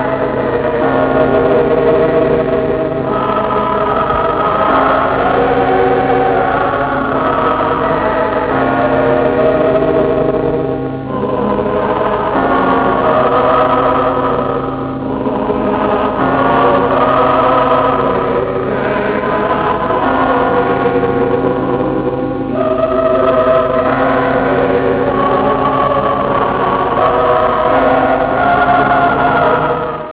Original track music